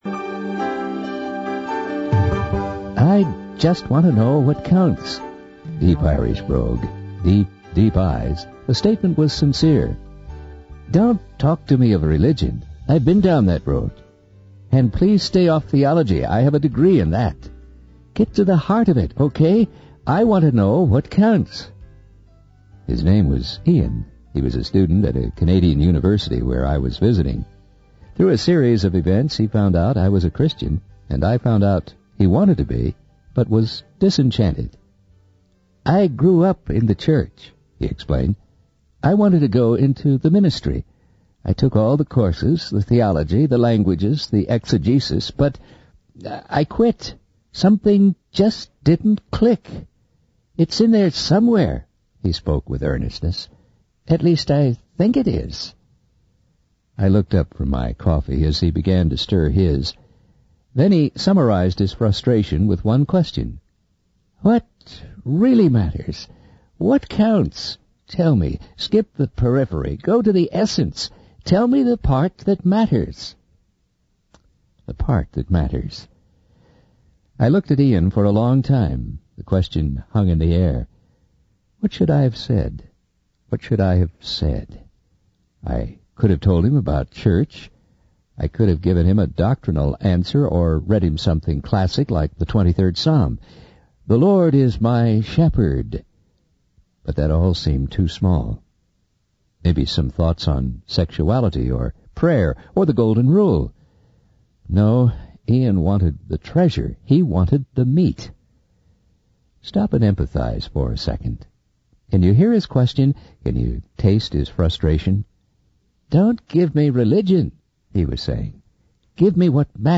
The video is a sermon that explores the nature of God and His love for humanity. It emphasizes that God's love is not based on passion and romance, but on a common mission and sacrifice.